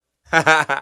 50 Cent - Laugh 01